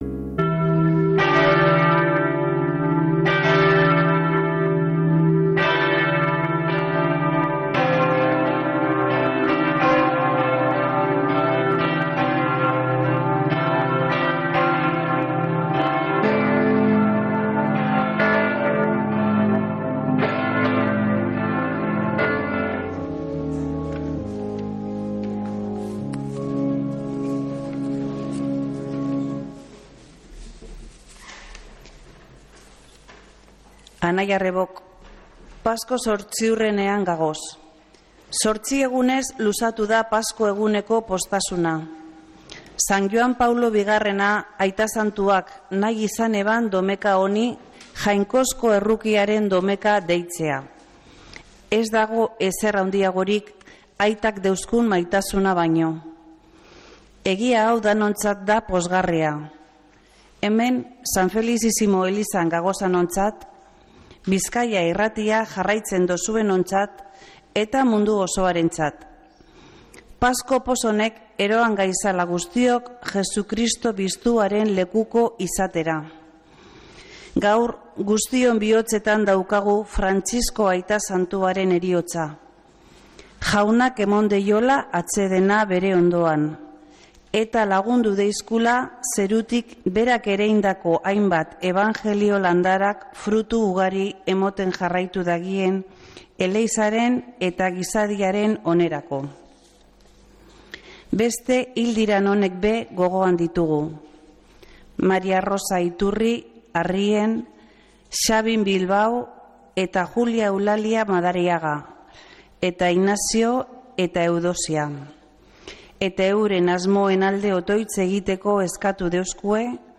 Mezea San Felicisimotik | Bizkaia Irratia